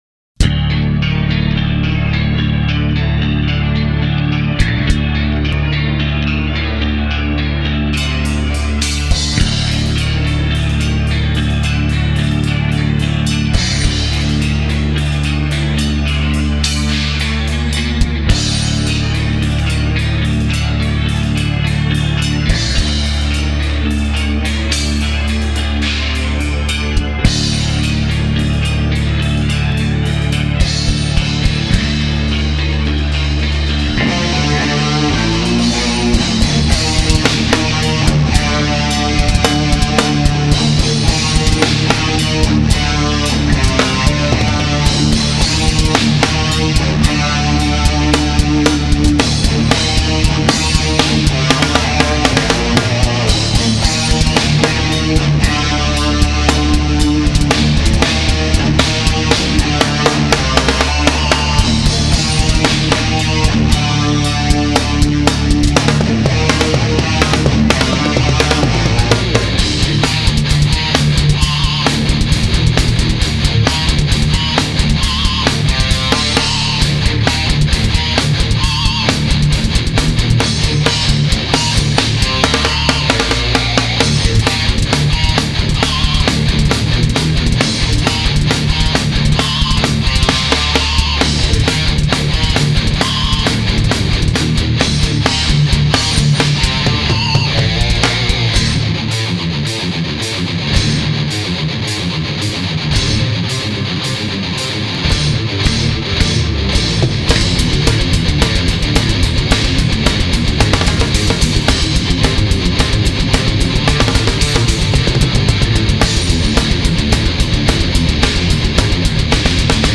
Heavy thrash metal from the UK!
Heavy metal
Thrash/hardcore